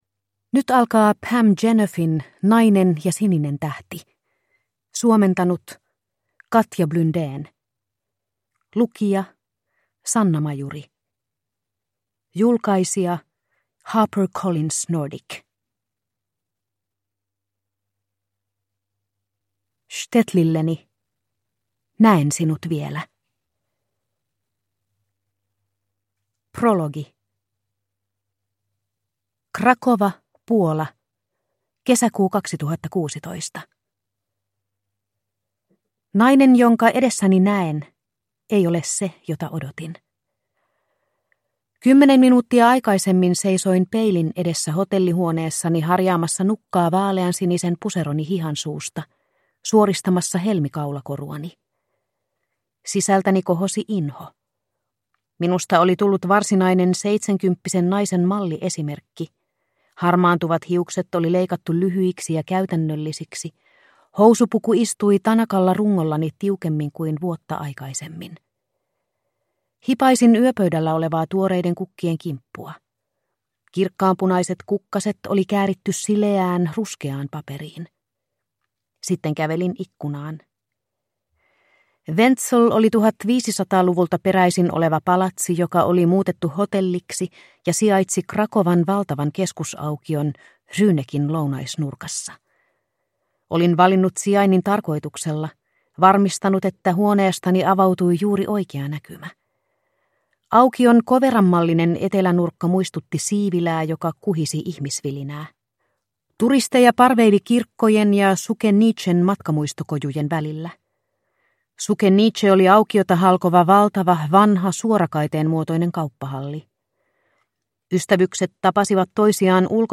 Nainen ja sininen tähti – Ljudbok – Laddas ner